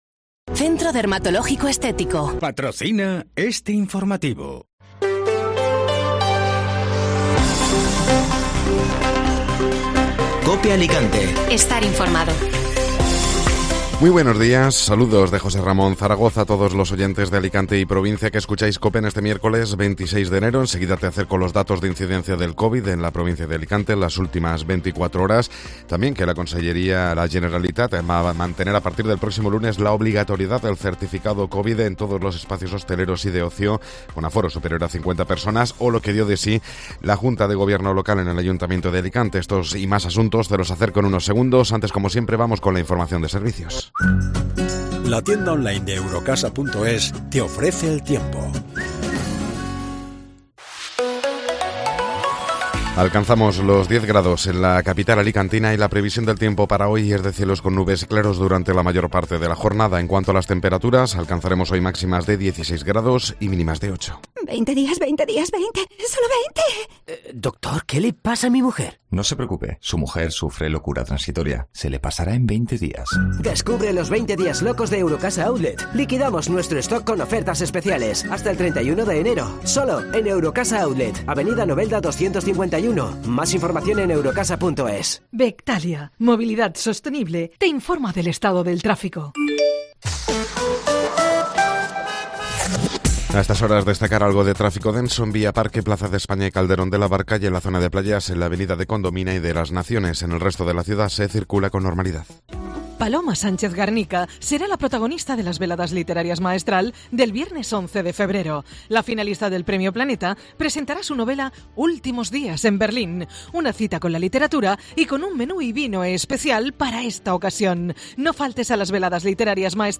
Informativo Matinal (Miércoles 26 de Enero)